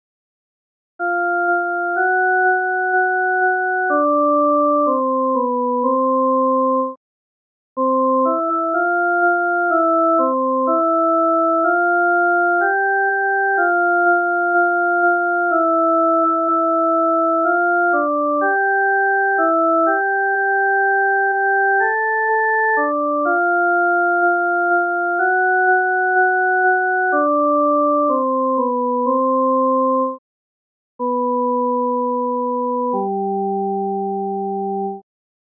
伴奏
女低